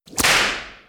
Angry.wav